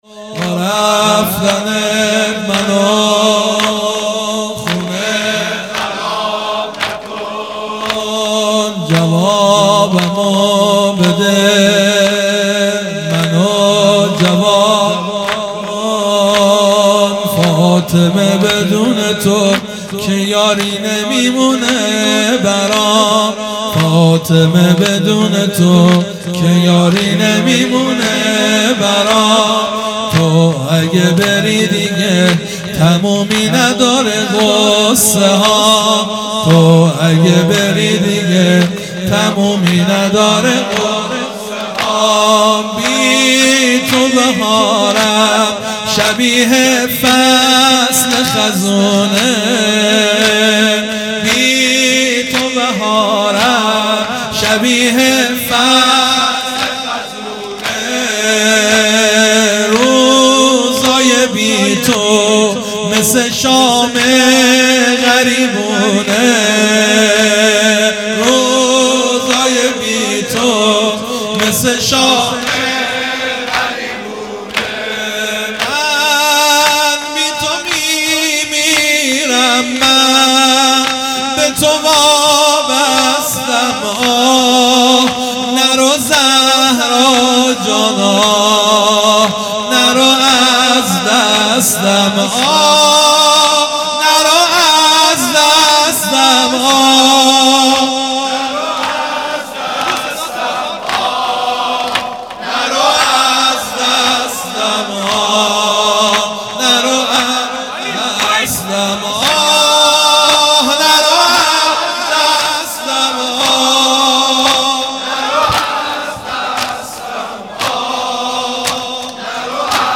هیئت مکتب الزهرا(س)دارالعباده یزد
1 0 زمینه | با رفتنت منو خونه خراب نکن مداح